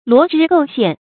罗织构陷 luò zhī gòu xiàn
罗织构陷发音